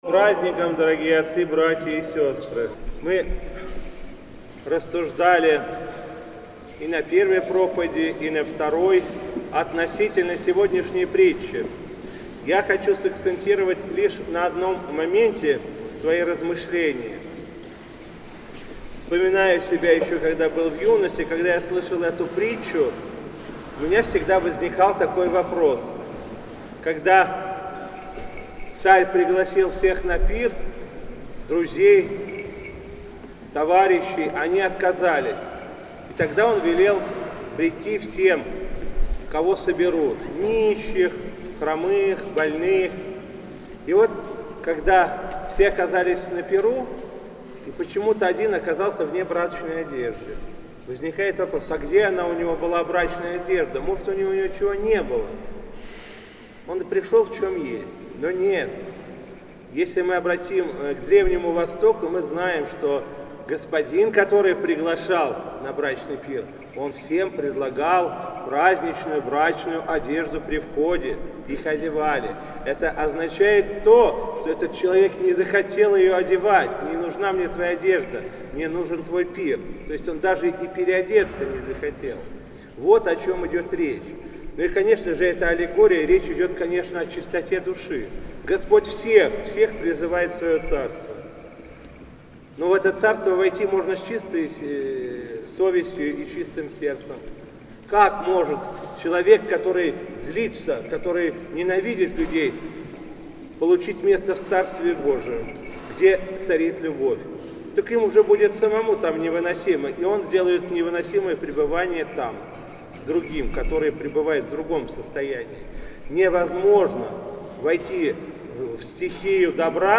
Владыка Игнатий поздравил прихожан с праздником и обратился к ним с проповедью, в которой подробно рассмотрел один из эпизодов притчи воскресного Евангельского чтения о званных на пир.